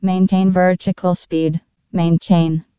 TCAS voice sound samples. ... Artificial female voice. Stubs until we have something more realistic. 2011-02-26 00:41:27 +01:00 60 KiB Raw History
maintain_vertical_speed.wav